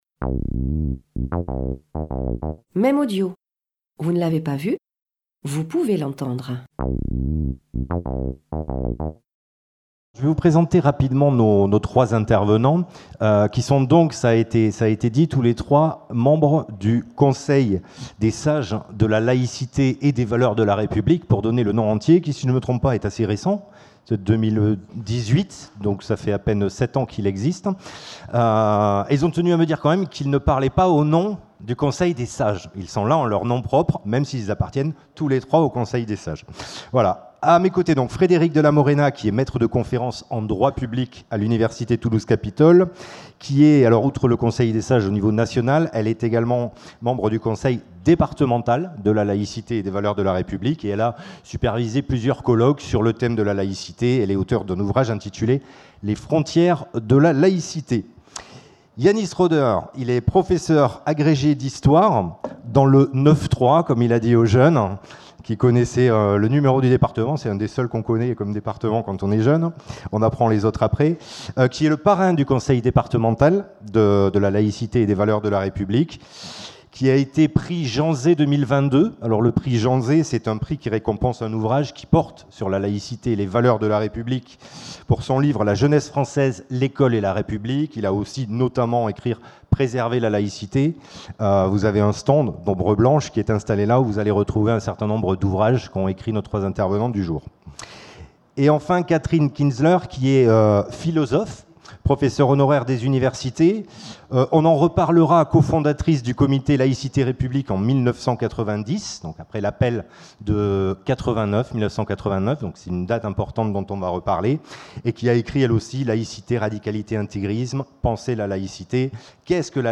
Table ronde
par Les chemins de la République - | Conseil Départemental de Haute-Garonne, Toulouse, le 9 décembre 2025